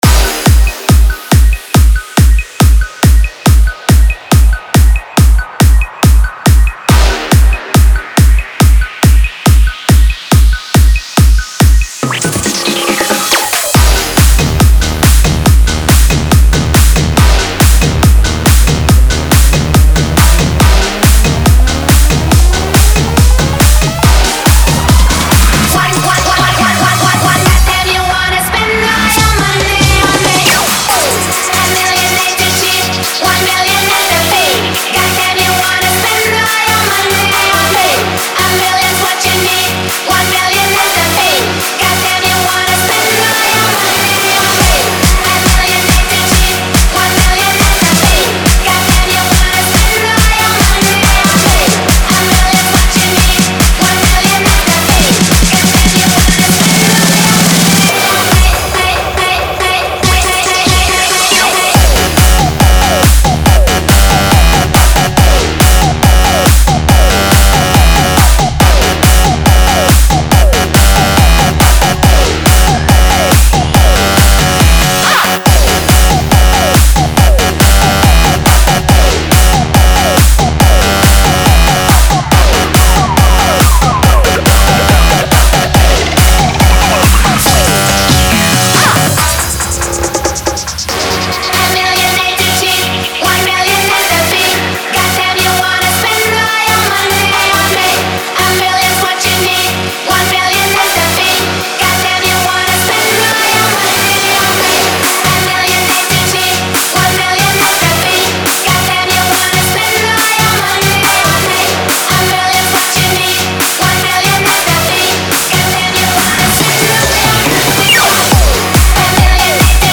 • Genre: Vina Bounce (Remix)
• Energy: High; dancefloor-ready build & drop
• Tempo: ~140 BPM (double-time friendly)
• Structure: intro/outro (DJ-friendly)